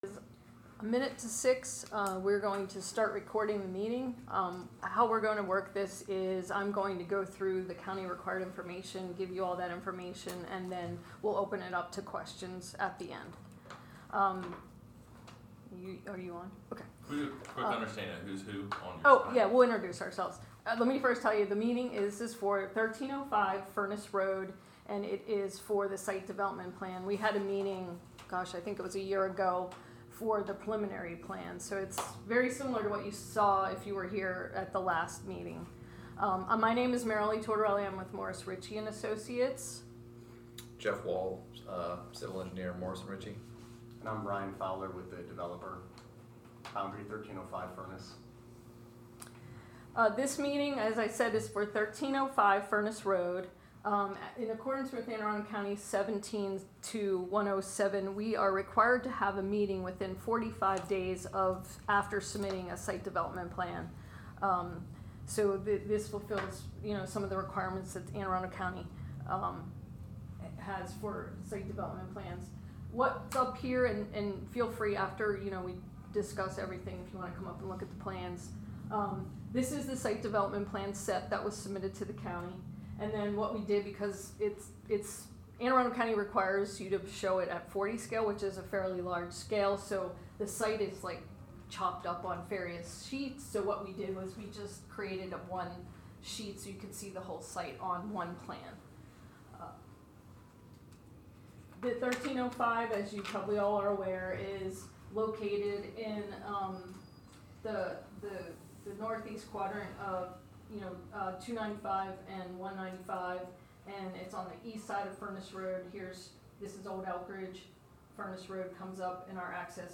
1305 Furnace Rd Final Plan Community Meeting – SDP Submittal